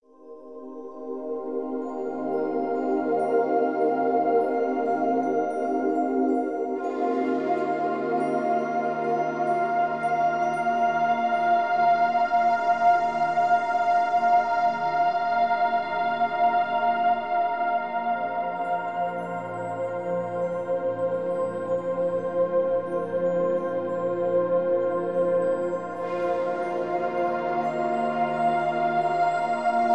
Hier ist die Musik OHNE Sprache.